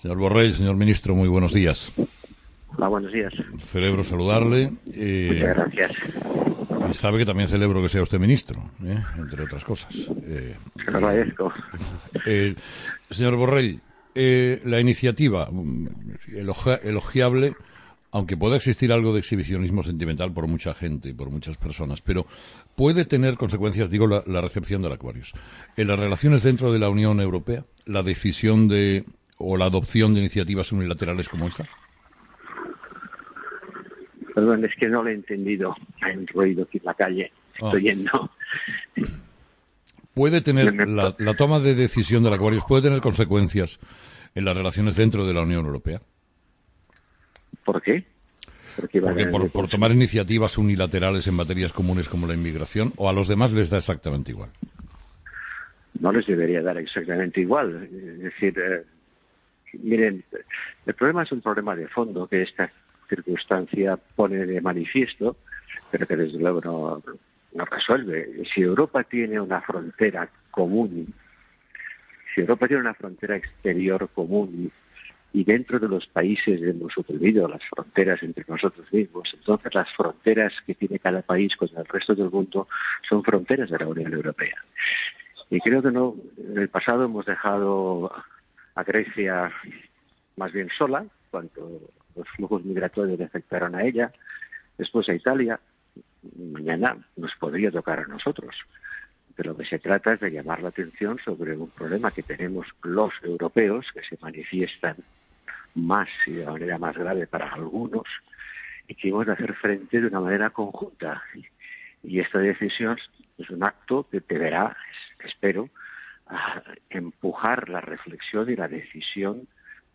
Entrevista: Josep Borrell COPE
Entrevistado: "Josep Borrell"